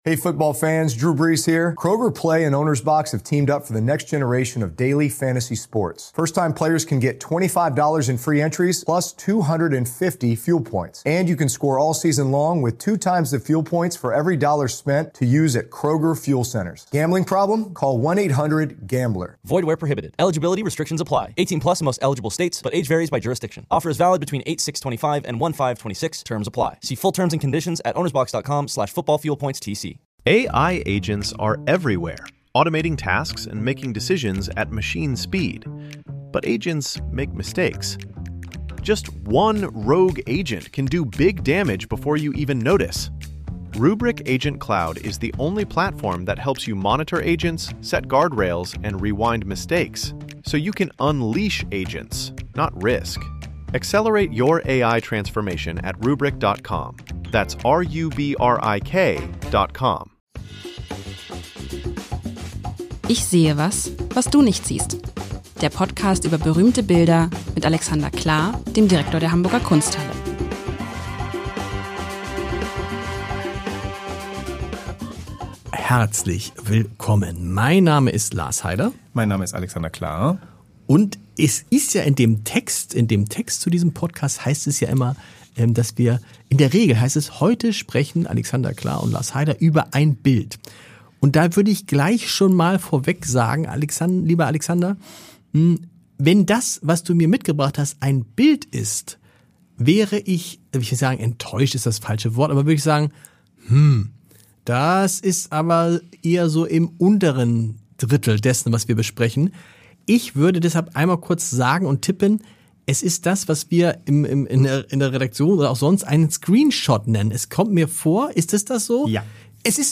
Unsere beiden Moderatoren freuen sich auf Ihre Fragen, auf Ihr Feedback und Ihre Anregungen.